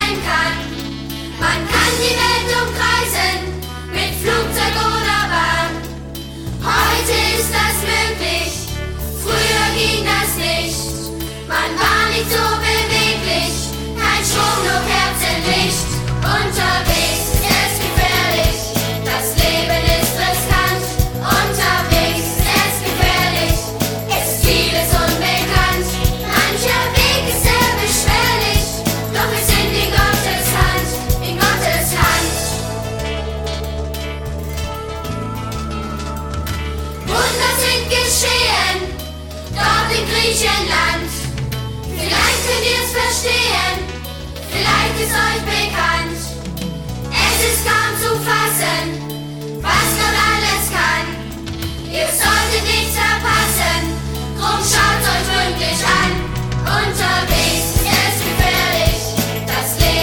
Ein Musical für die ganze Familie
Mit fetzigen Liedern und modernen Arrangements.
Kinderlieder